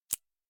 click.ogg